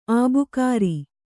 ♪ ābukāri